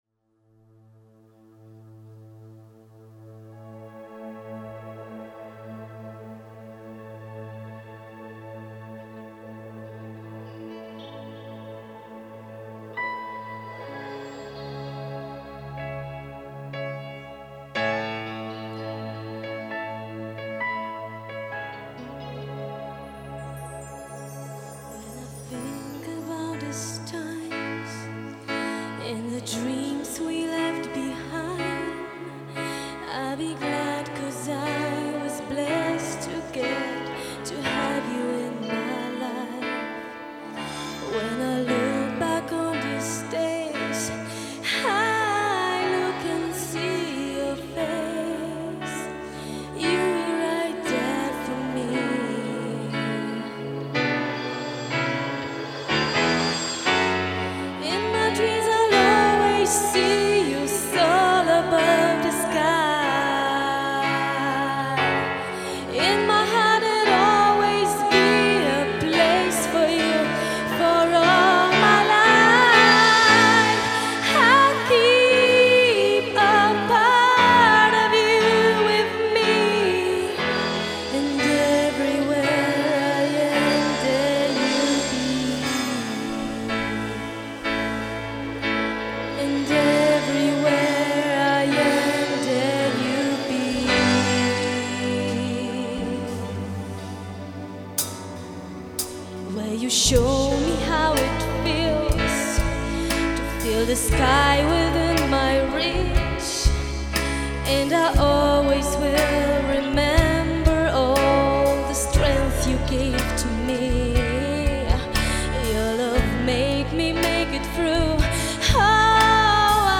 Nagrania LIVE (z koncertu):